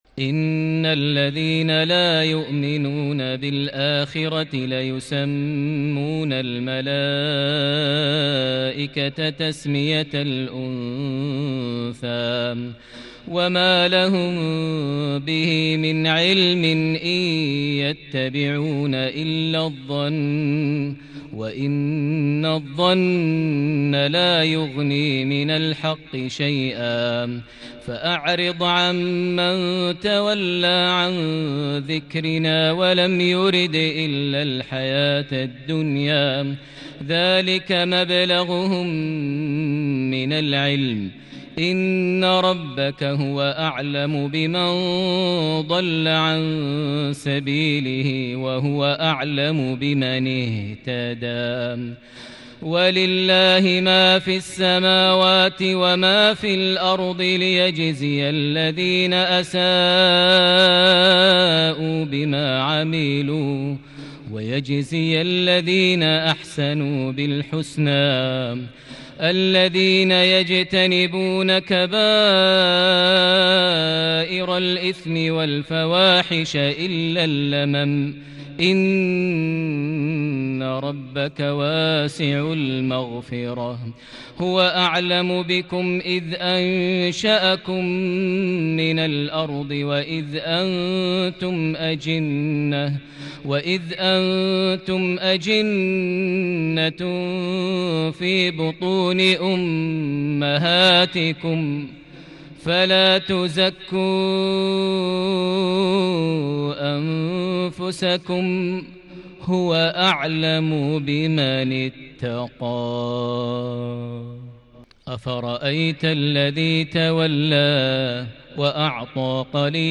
عشاء 1 شعبان 1440هـ من سورة النجم | Isha prayer from Surat AnNajm 6- 4- 2019 > 1440 🕋 > الفروض - تلاوات الحرمين